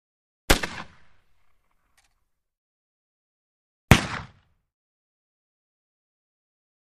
Kentucky Rifle: Single Fire ( 2x ); Two Shots From Kentucky Rifle. Sharp, Loud Musket Fires. First Shot Is Followed By Faint Rifle Drop Or Set Down. Close Up Perspective. Gunshots.